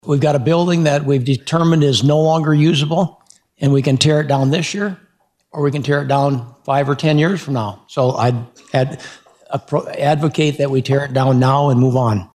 Rep. Al Novstrup from Aberdeen also urged fellow lawmakers to pass the bill.